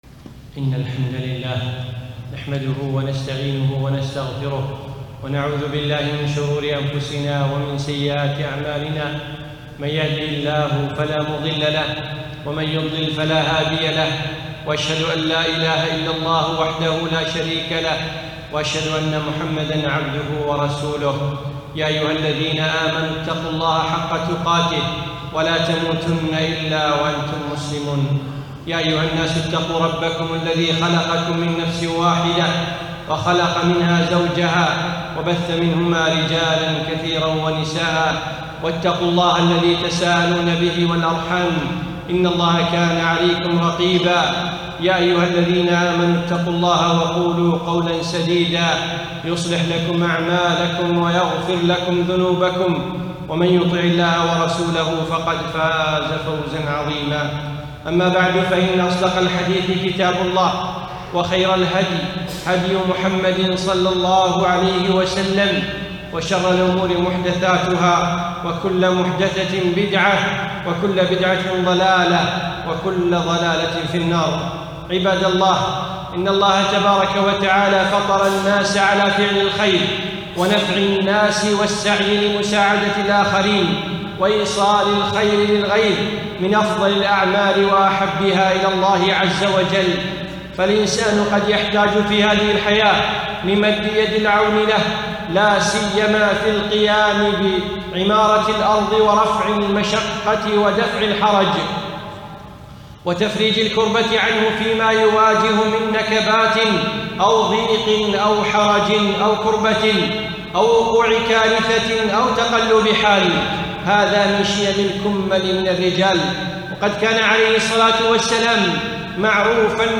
يوم الجمعة 22ذو العقدة 1436هـ 5 9 2015م في مسجد عائشة المحري المسايل